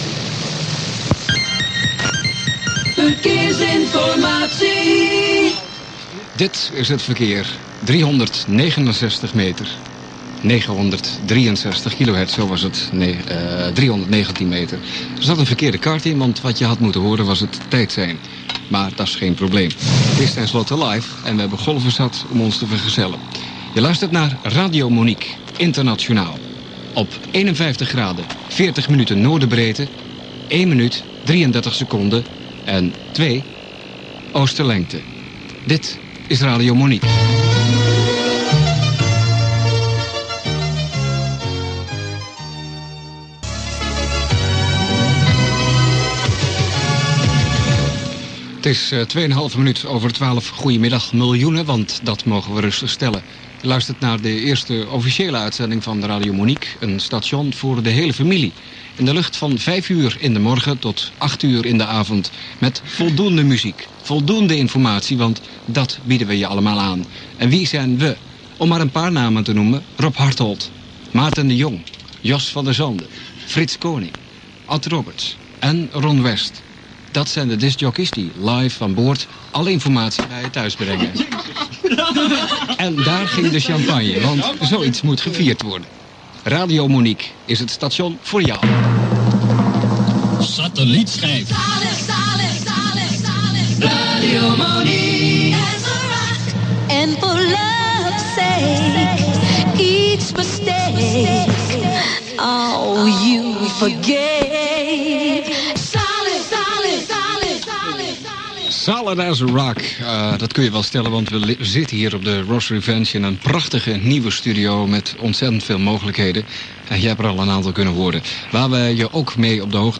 click to hear audio The opening of Radio Monique, 11am GMT, 12 noon CET, 16th December 1984.
The opening music was the theme to the TV show Terrahawks (duration 2 minutes 35 seconds)